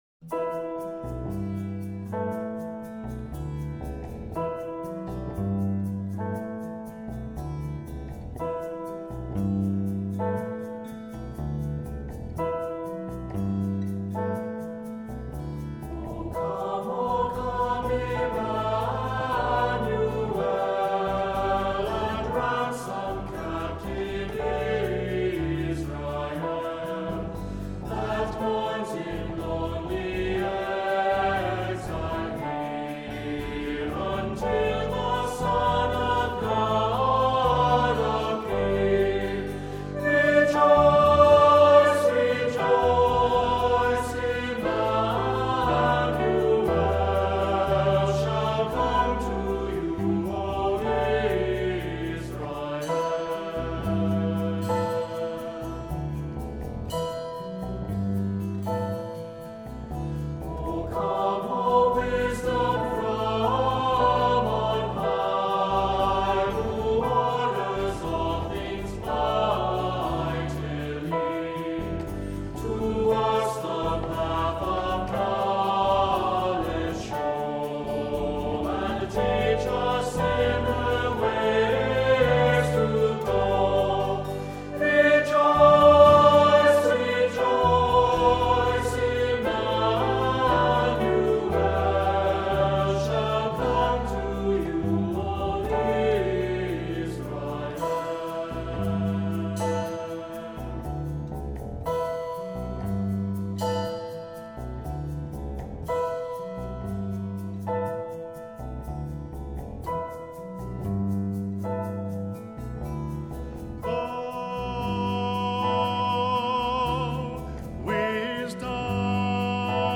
Accompaniment:      Keyboard
Music Category:      Christian
Advent processional